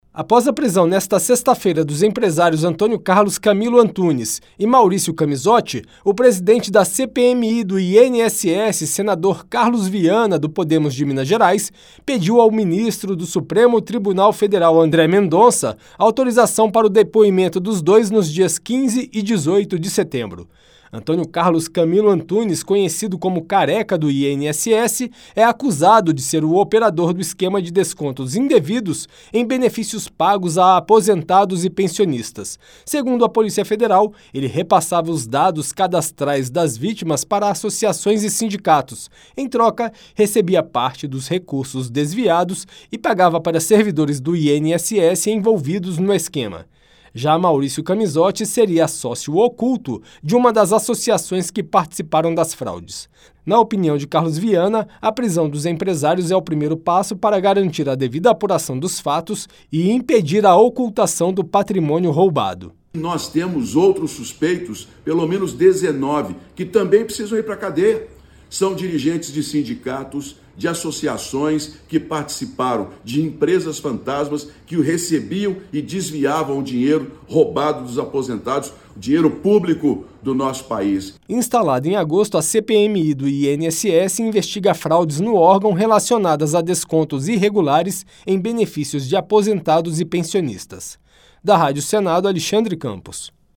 Senador Carlos Viana